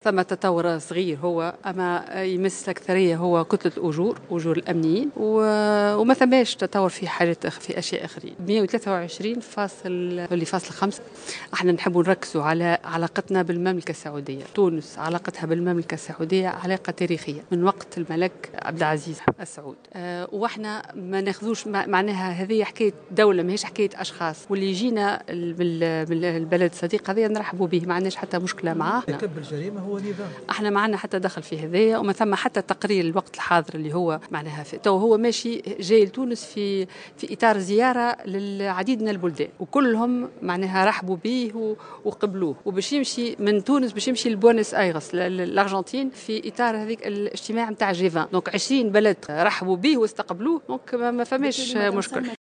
وقالت في تصريح لمراسلة "الجوهرة أف أم" في تعليقها على زيارة مرتقبة لولي العهد السعودي محمد بن سلمان إلى تونس، غدا الثلاثاء، إن الأمر يتعلق بالدولة وليس بأشخاص وكل من يزور تونس مرحّب به"، وفق تعبيرها.